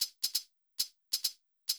GAR Beat - Mix 8.wav